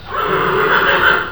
c_horsexxx_atk2.wav